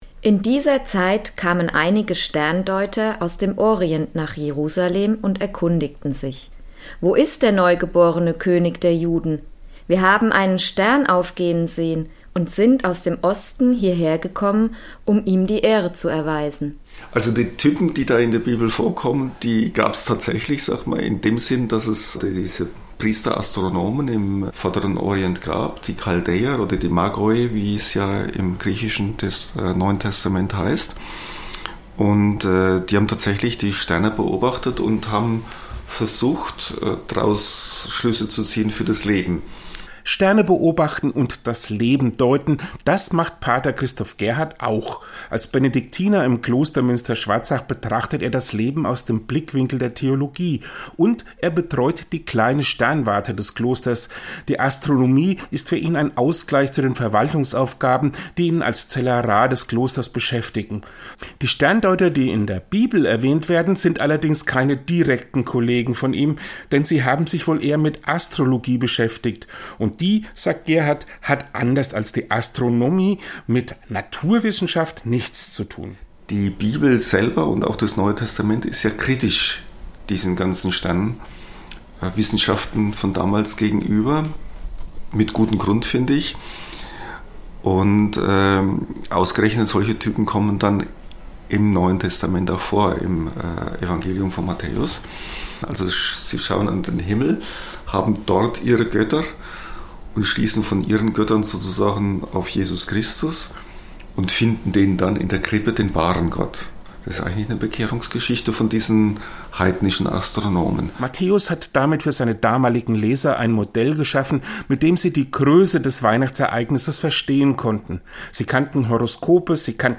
Benediktinerpater gesprochen, der sowohl Theologe als auch Astronom